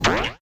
bubble3.wav